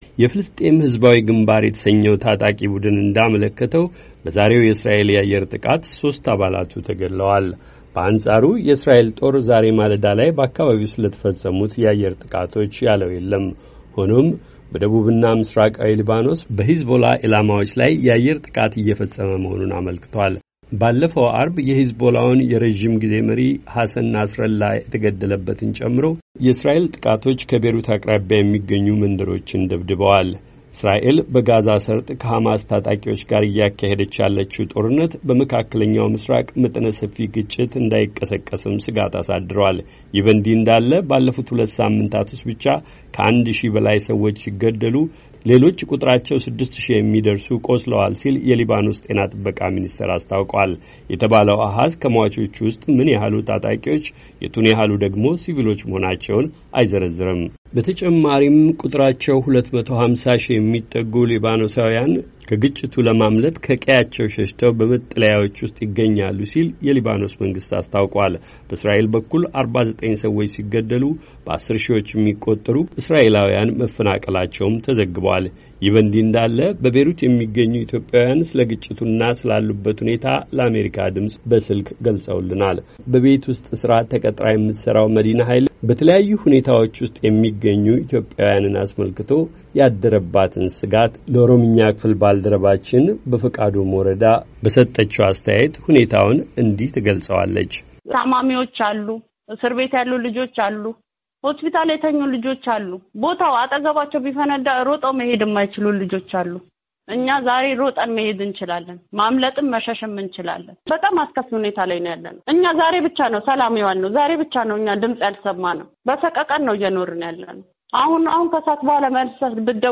የሚገኙበትን ሁኔታ የገለጹልንን የቤሩት ነዋሪ ኢትዮጵያዋያን አስተያየት ጨምሮ ሰሞንኛውን የእስራኤል እና የሂዝቦላ ግጭት የተመለከ ዘገባ ከተያያዘው የድምጽ ፋይል ይከታተሉ።